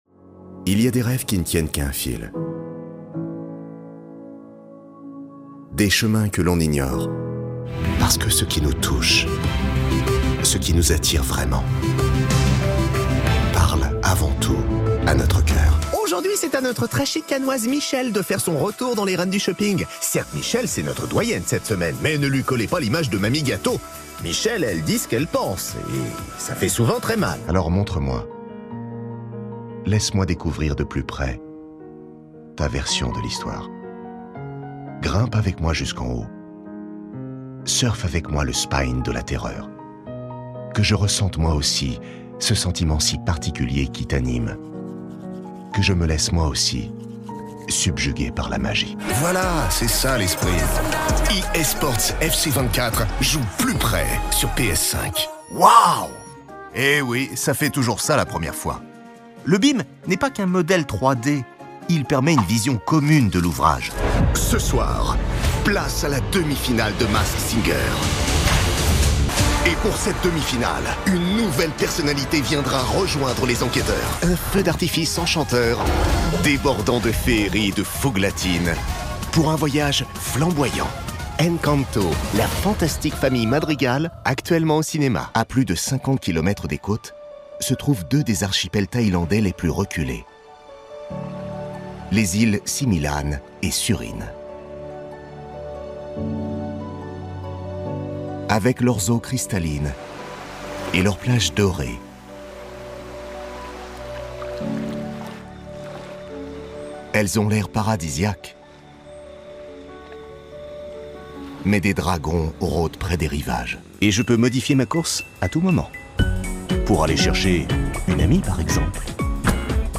La voix off masculine pro de référence
Avec sa voix au timbre médium grave